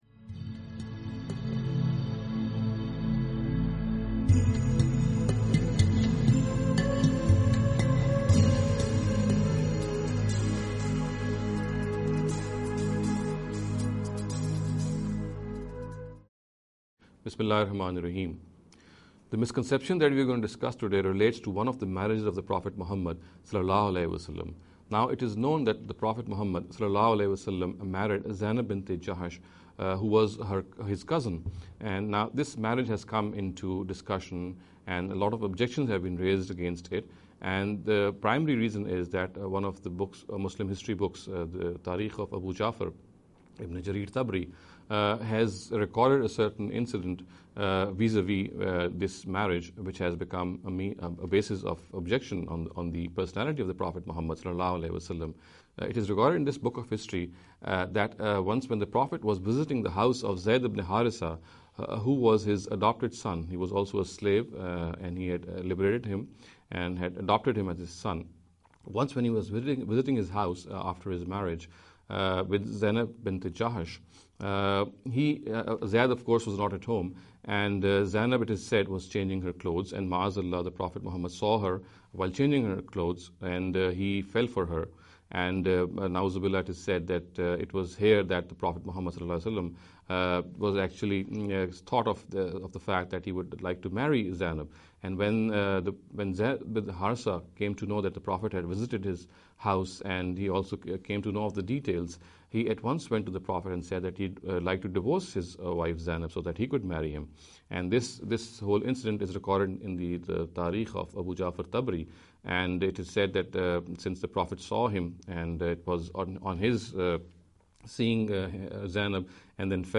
This lecture series will deal with some misconception regarding the Concept of Prophethood.